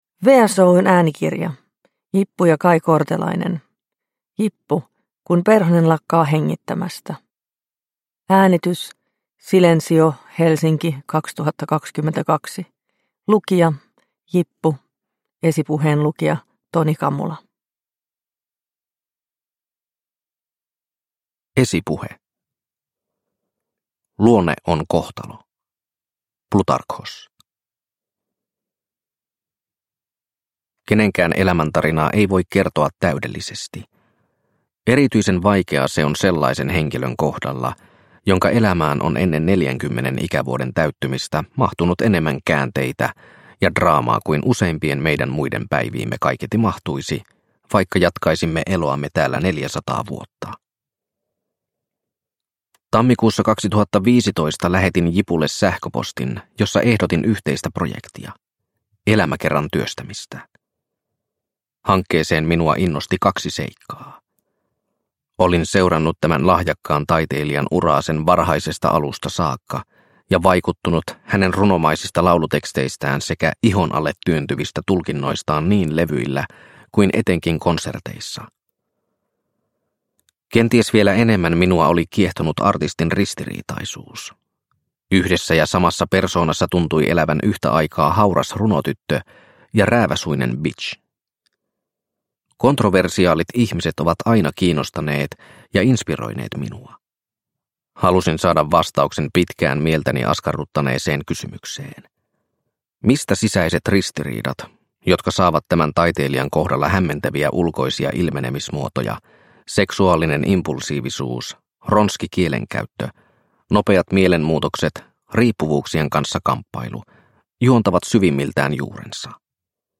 Jippu - Kun perhonen lakkaa hengittämästä – Ljudbok – Laddas ner
Uppläsare: Jippu